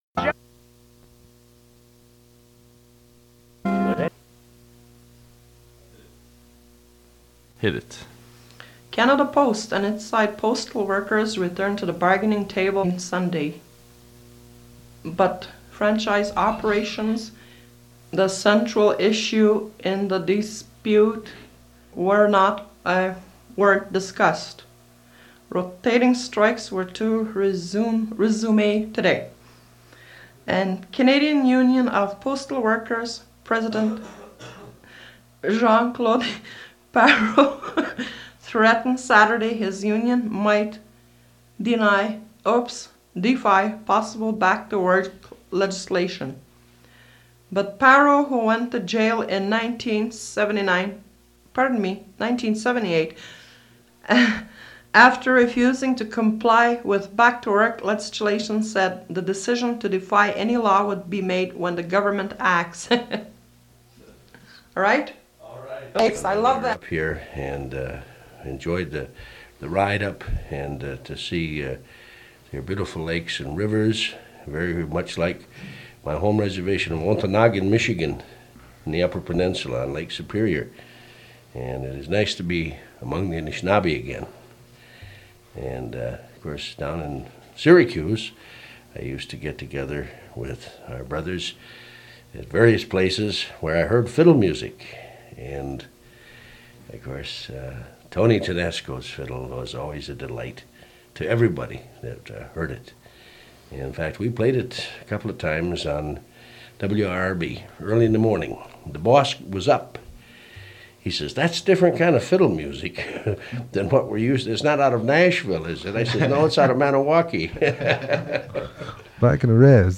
Fait partie de Radio program on culture and music